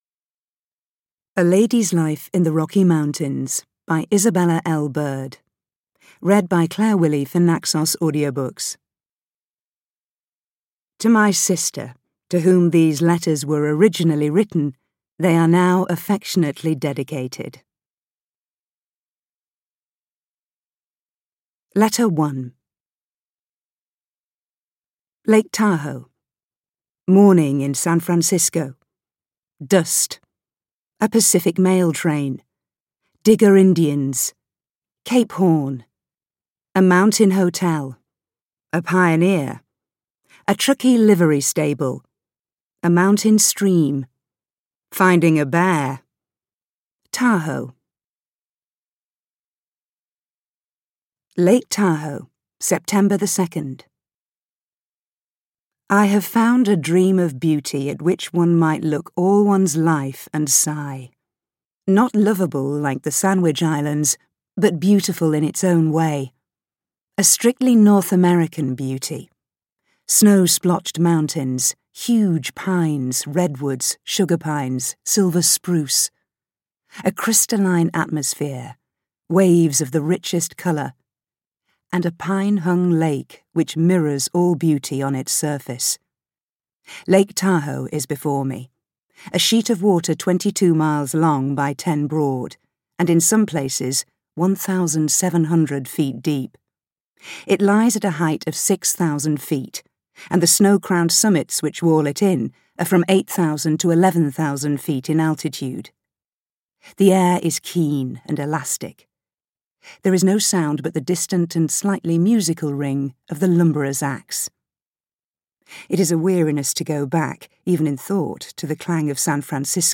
A Lady’s Life in the Rocky Mountains audiokniha
Ukázka z knihy